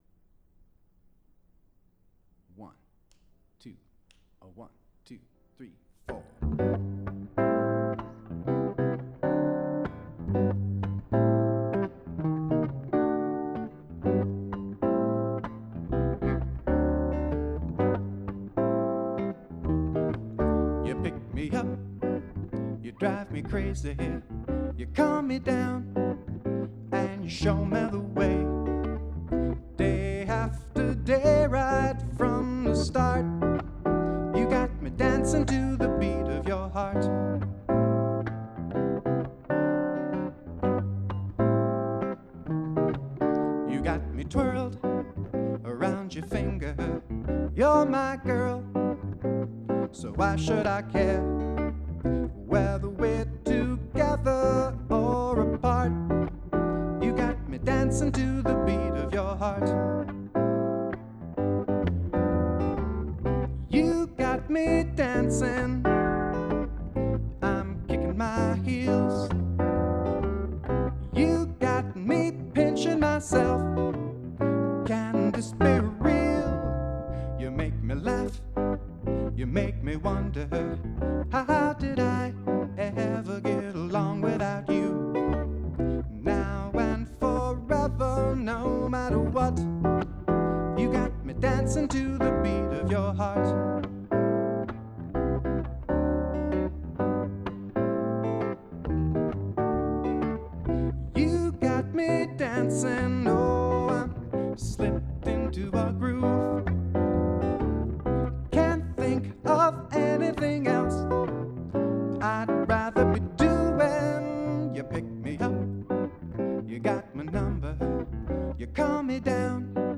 ” a swinging little love song that was
self-produced, cassette-tape-only album
Electric Guitar & Vocals